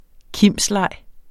Udtale [ ˈkims- ]